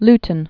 Lu·ton
(ltn)